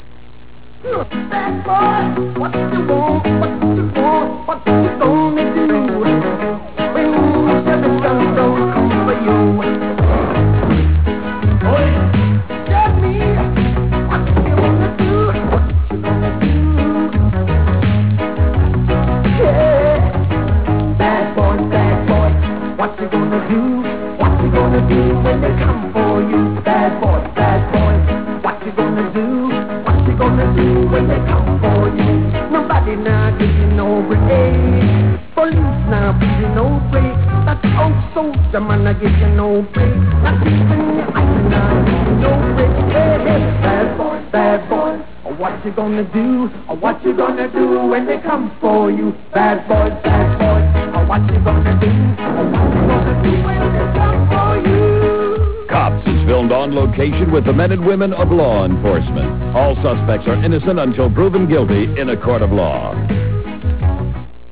TV Themes